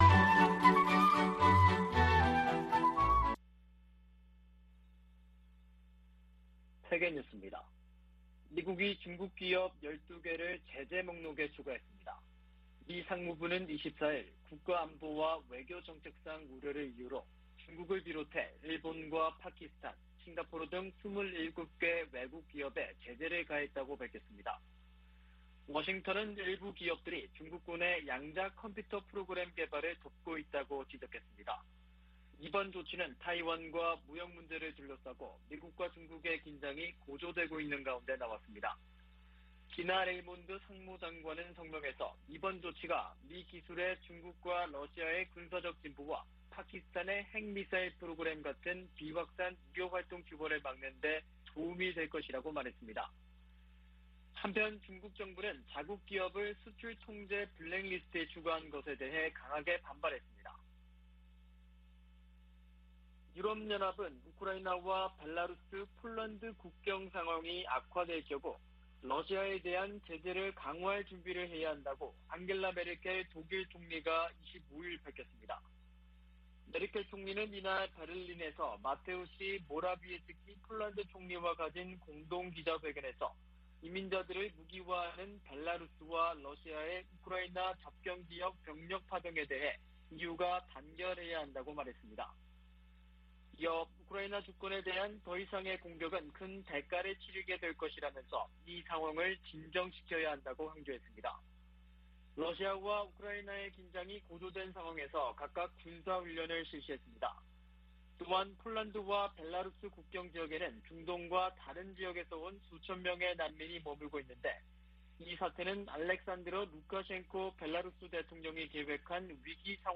VOA 한국어 아침 뉴스 프로그램 '워싱턴 뉴스 광장' 2021년 11월 26일 방송입니다. 국제원자력기구(IAEA)는 영변 핵 시설에서 새로운 활동이 관찰되고 평산과 강선의 관련 시설에서도 지속적인 활동 징후가 포착됐다고 밝혔습니다. 북한은 의도적 핵활동 노출로 미국을 압박하고 이를 협상력을 높이는 지렛대로 활용하려는 계산이라고 한국의 전문가가 분석했습니다. 미국과 한국의 한국전쟁 종전선언 논의가 문안을 마무리하는 단계에 있다고, 미국 정치 전문 매체가 보도했습니다.